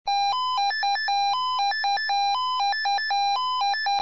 Alarmierung
2002 - 2003  vom Typ Swissphone Quattro XL